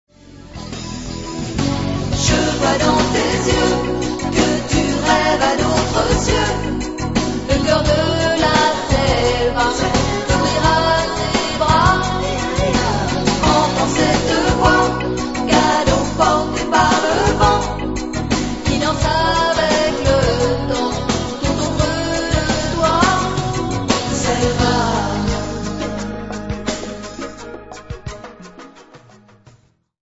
Guitare, clavier
Batterie
Basse
Flûtes
Percussions